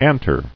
[an·tre]